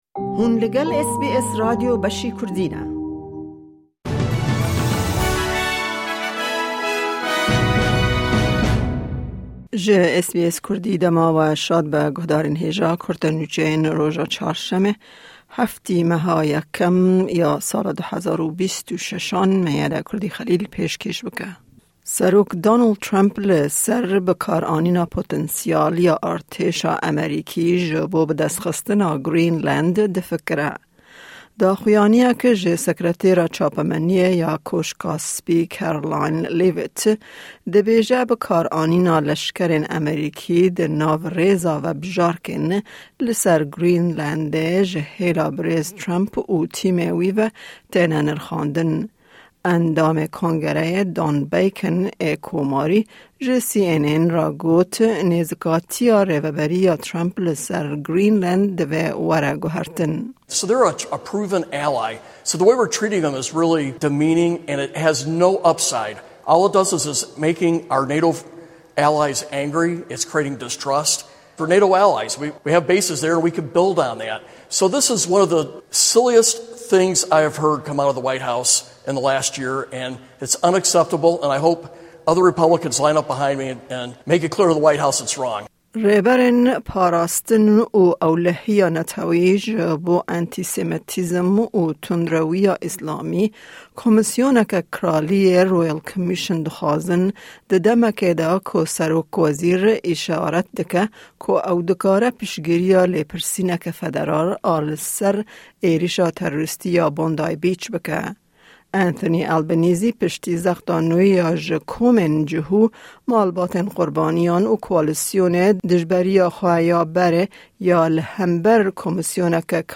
Nûçeyên roja Çarşemê 07/01/2026